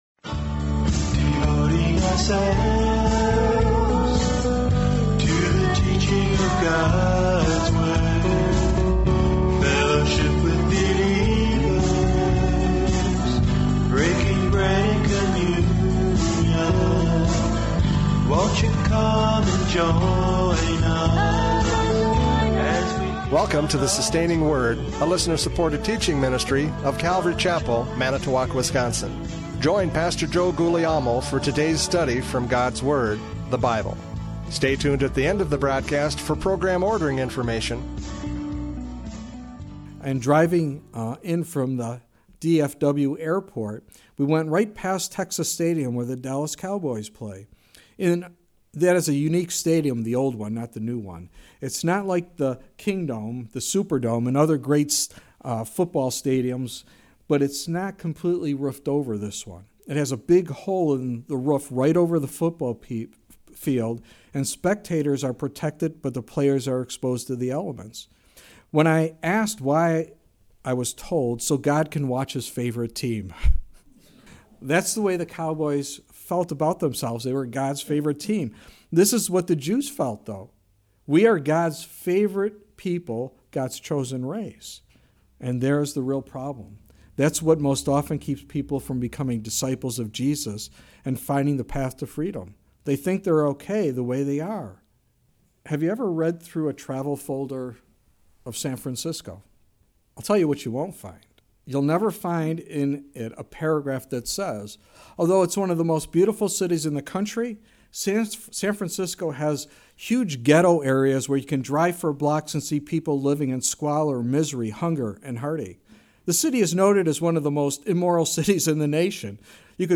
John 8:31-38 Service Type: Radio Programs « John 8:31-38 Freedom in Christ!